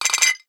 NOTIFICATION_Glass_05_mono.wav